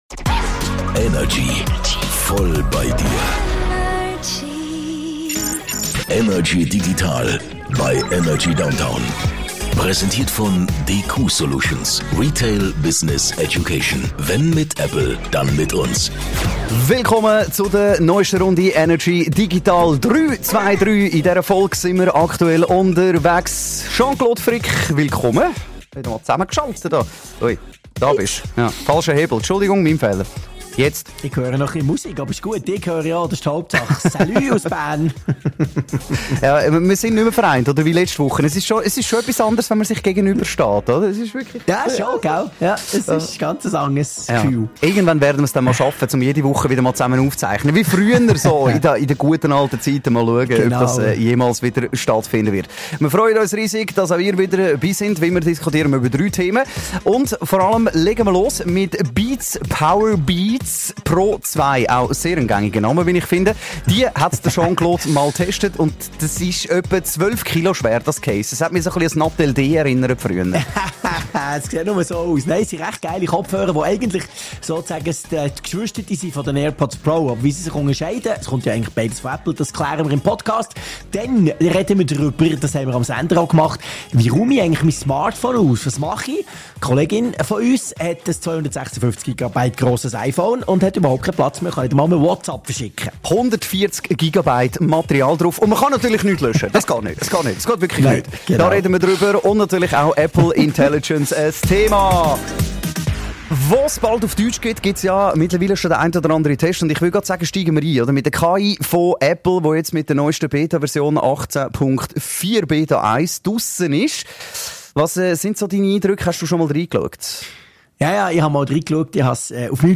aus dem HomeOffice über die digitalen Themen der Woche ..